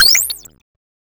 freeze.wav